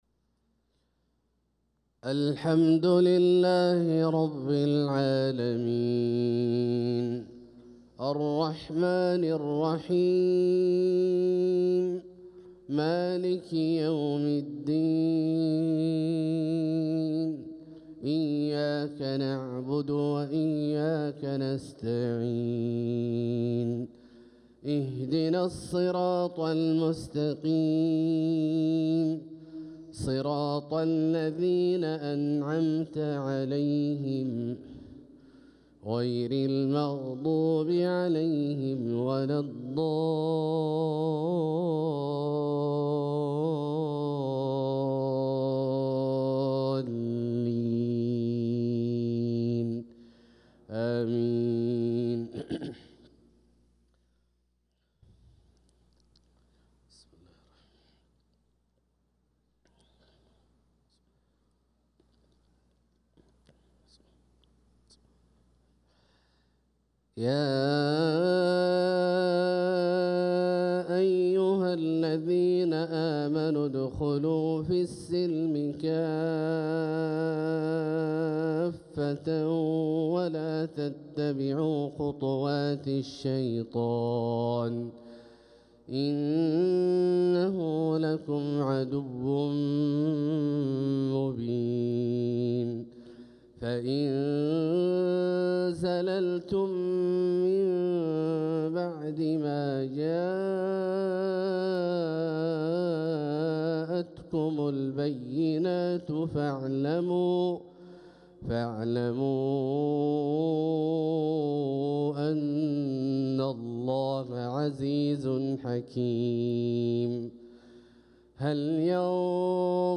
صلاة الفجر للقارئ عبدالله الجهني 14 ربيع الآخر 1446 هـ
تِلَاوَات الْحَرَمَيْن .